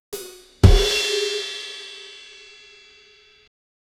rimshot joke drum sound, ba dum tss, comedy punchline, live drumset, snare drum hits, ride cymbal, ride cymbal bell hit, ride cymbal ding, cymbal quickly muted, bass drum hit with cymbal 0:04 Created Jun 25, 2025 5:27 PM
rimshot-joke-drum-sound-b-h3uxgvcj.wav